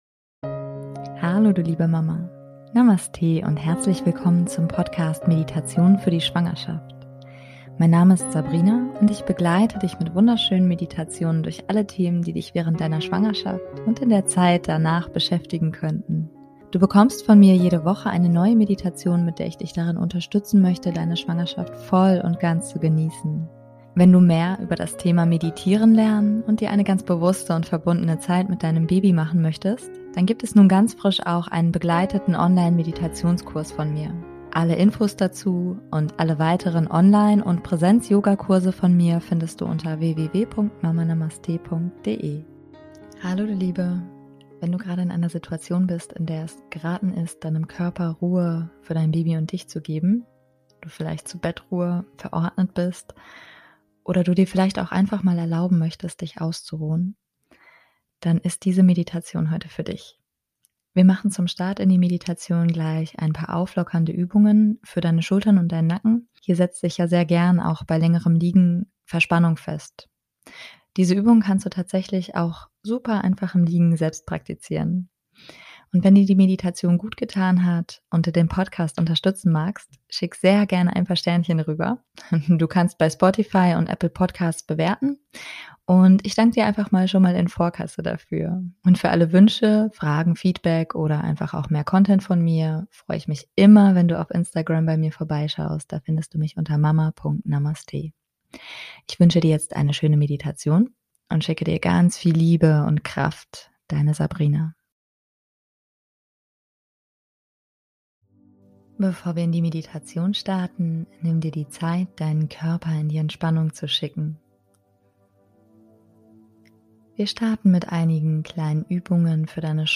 Hallo du Liebe! Wenn du gerade in einer Situation bist, in der es geraten ist deinem Körper Ruhe für dein Baby und dich zu geben, du vielleicht zu Bettruhe “verordnet” bist oder du dir einfach mal erlauben möchtest auszuruhen, dann ist diese Meditation heute für dich.